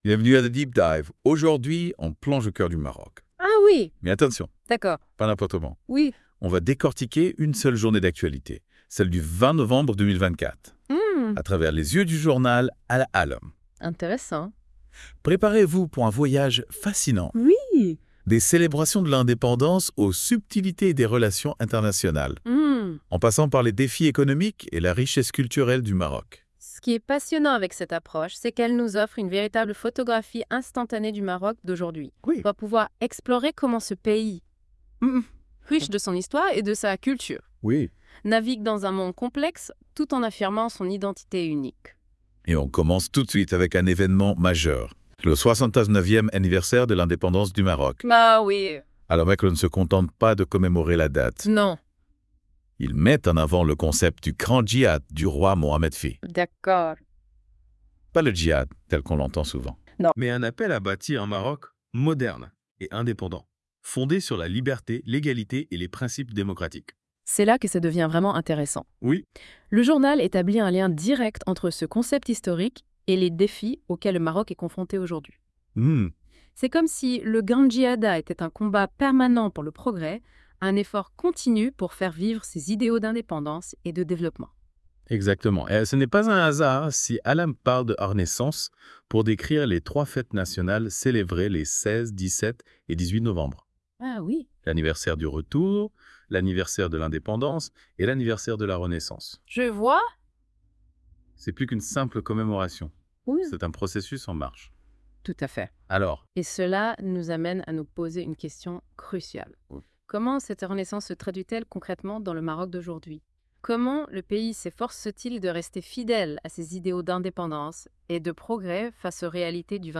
L'ODJ Média annonce humblement que l'utilisation de cette technologie, encore en version bêta, peut engendrer quelques erreurs de prononciation, notamment sur certains noms propres ou termes spécifiques.